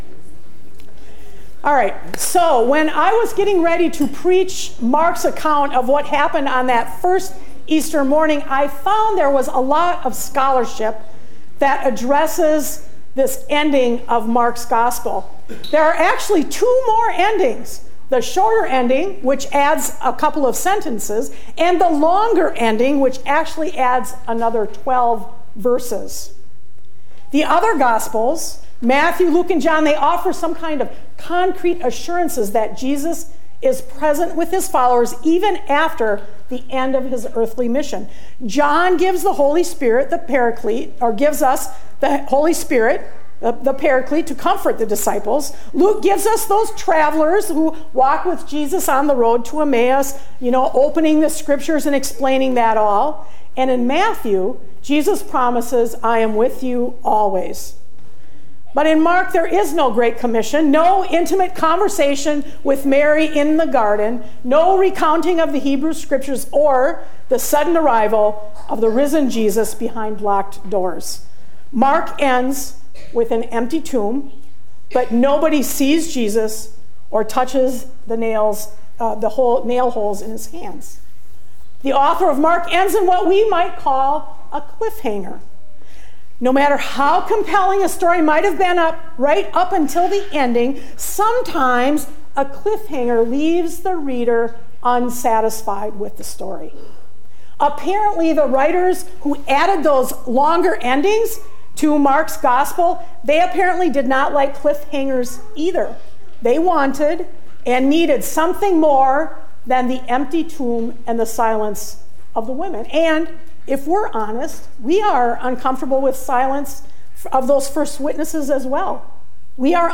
Sermons | Eleva Lutheran Church